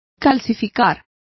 Complete with pronunciation of the translation of calcifies.